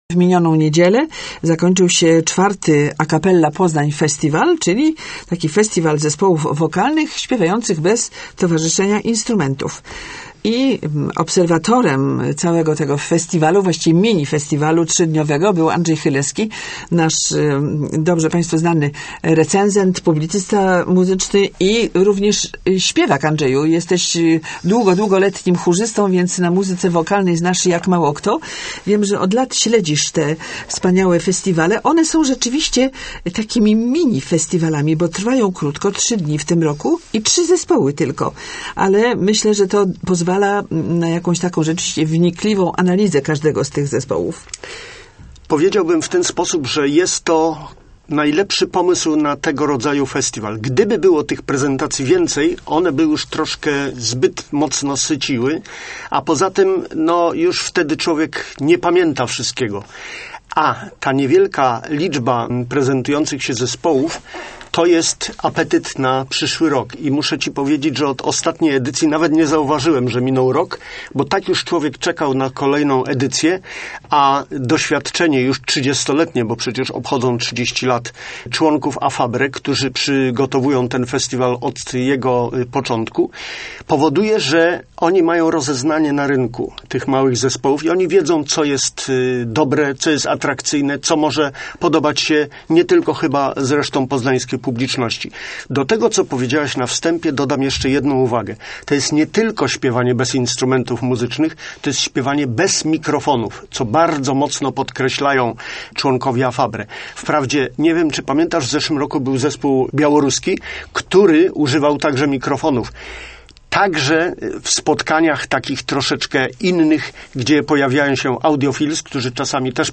szwedzki zespół